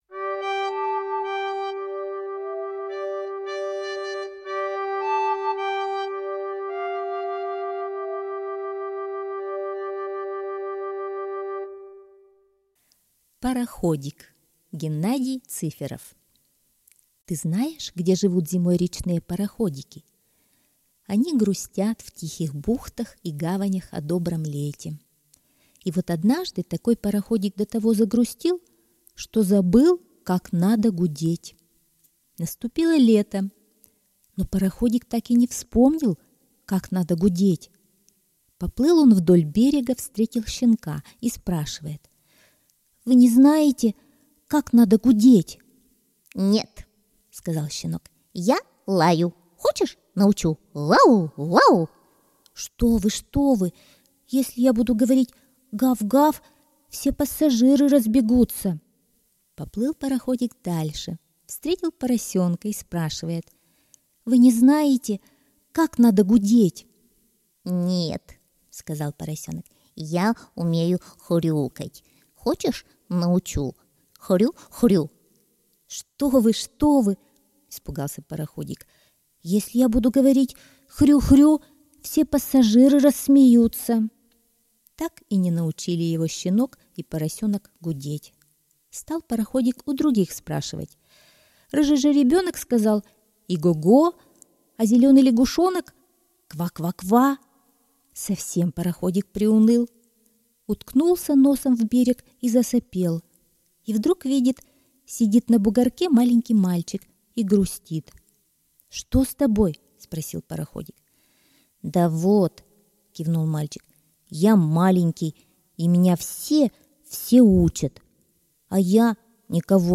Слушайте онлайн Пароходик - аудиосказка Цыферова Г.М. Короткая история о пароходике, который забыл, как надо гудеть...